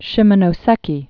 (shĭmə-nō-sĕkē, shēmô-nô-)